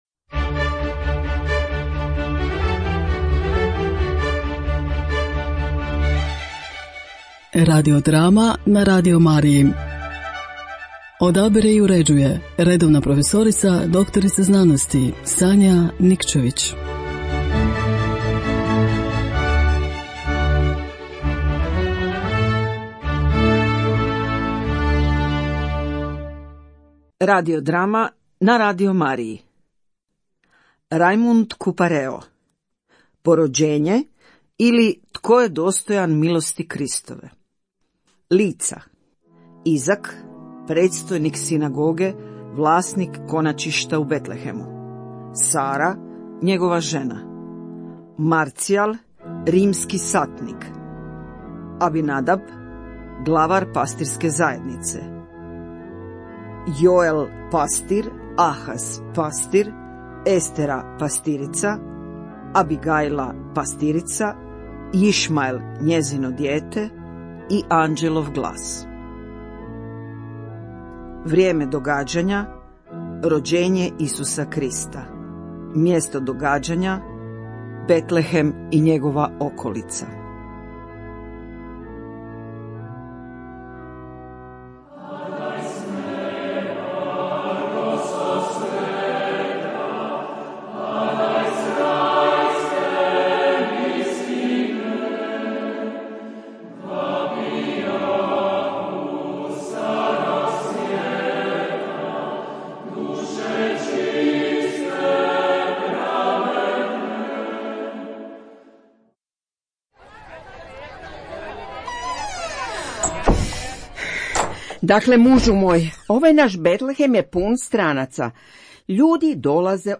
Radio drame na Radio Mariji